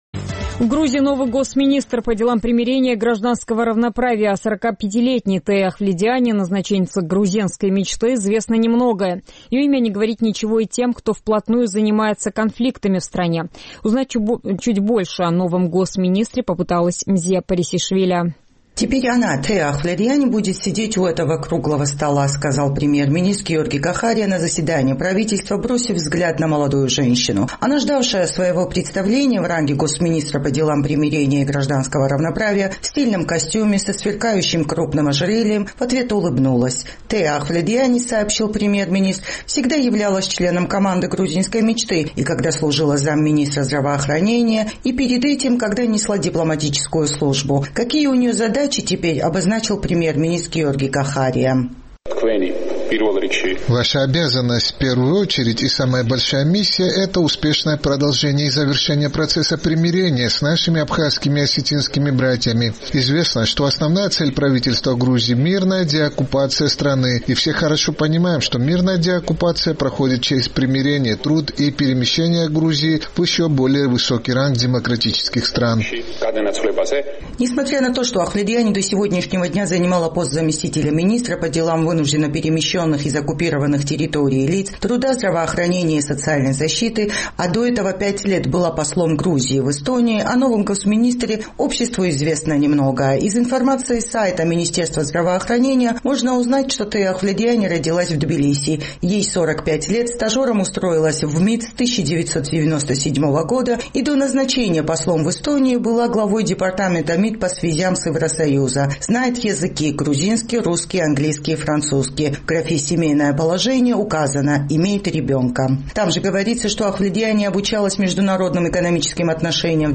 «Это еще одно деструктивное и вероломное проявление оккупационного режима. Правительство Грузии, наше ведомство в сотрудничестве с другими ведомствами прикладывает все усилия для освобождения задержанных людей», – чуть запнувшись, но твердым голосом заявила министр и покинула зал.